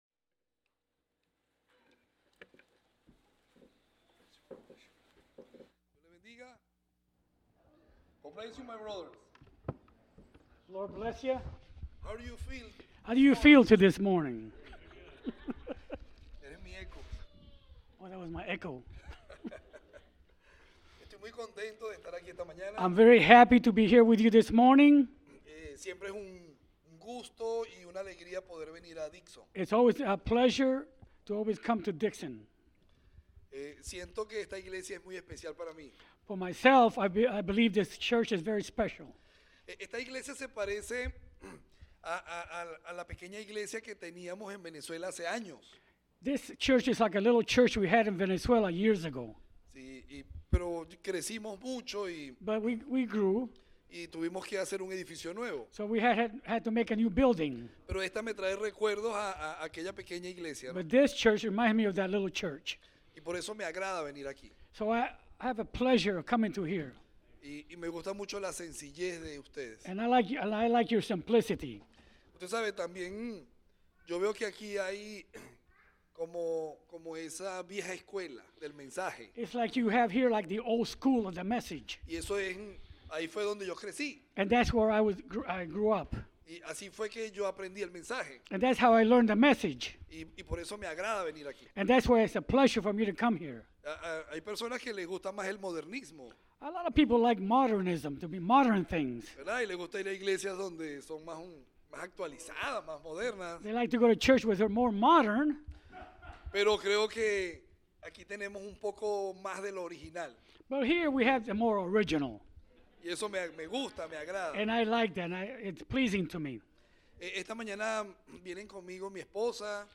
Preached December 31